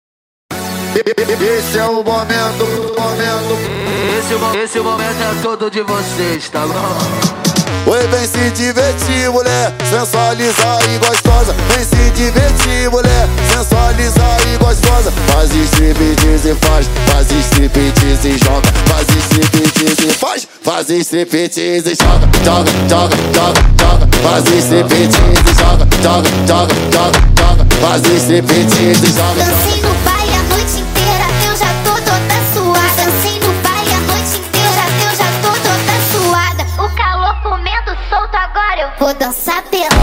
Categoria Rap